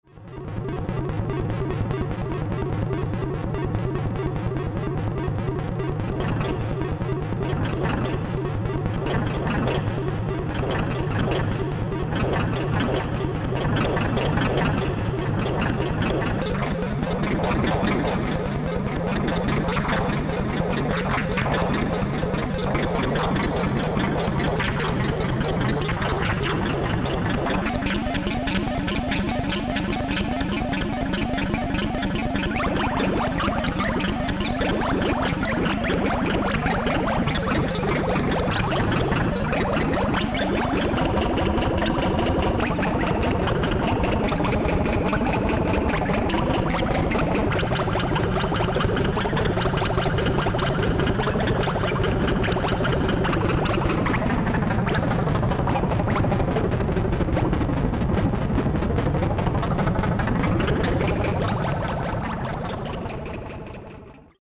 prototype electronic musical instruments
Unlike the self destructing vacuum tube circuits the Barrons used, the Martian Music Machines are solid state integrated analog synthesizers similar to the instruments made during the mid 1960's by Bob Moog and Don Buchla. Many of the complex tonal modulation sounds seem to take on lives of their own as they sing or sometimes scream in and out of existence.